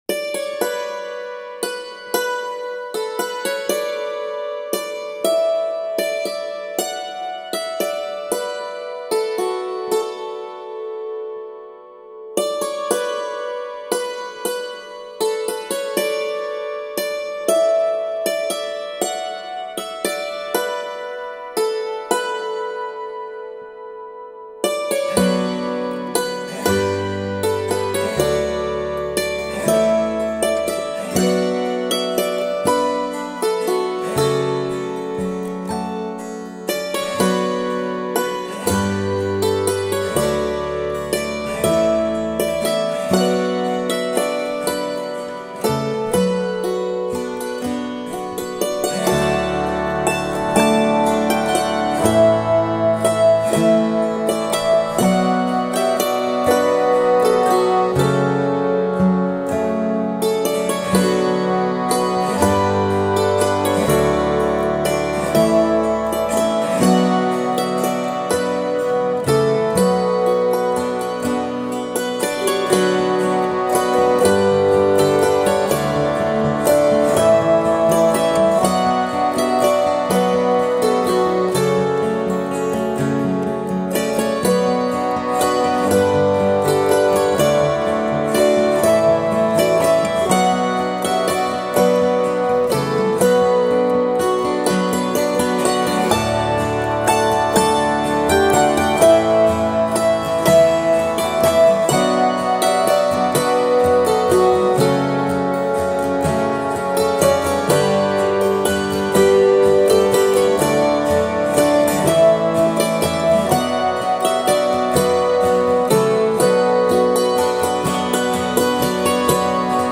AMERICAN FOLK MELODIES